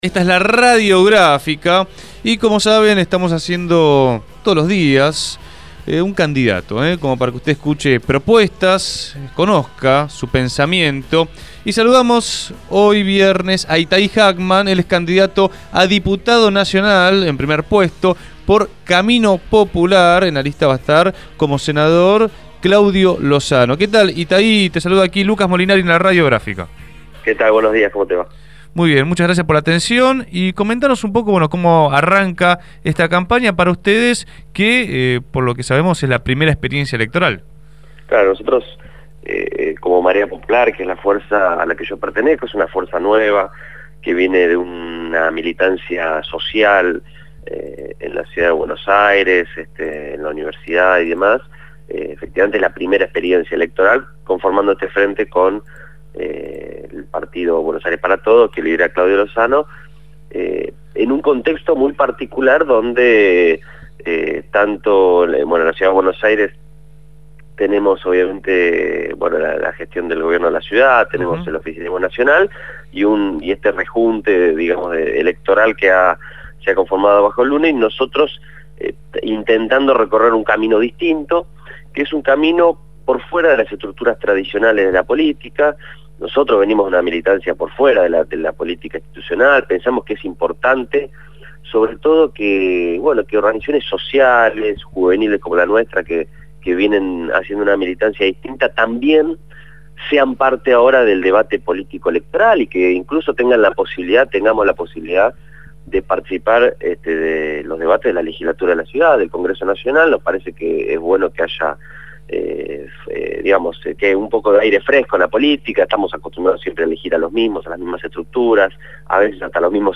fue entrevistado en Punto de Partida